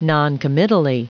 Prononciation du mot noncommittally en anglais (fichier audio)
Prononciation du mot : noncommittally